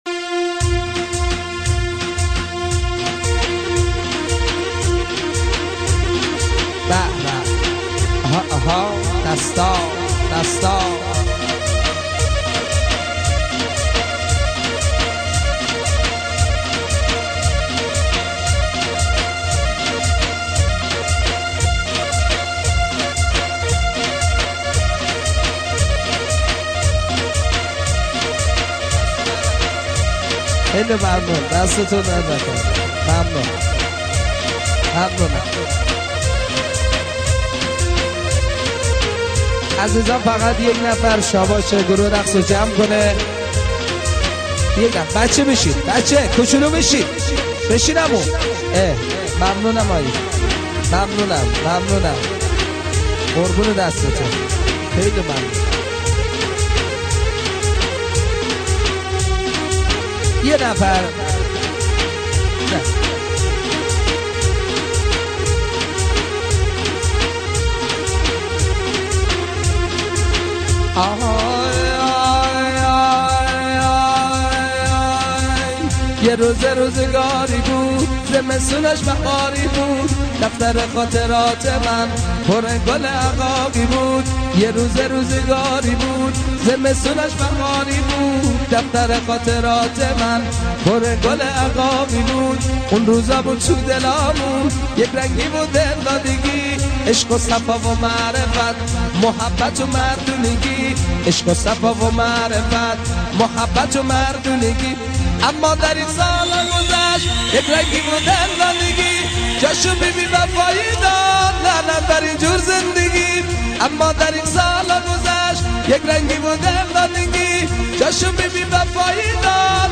یکی از خواننده های پرشور و پرانرژی کرمانجی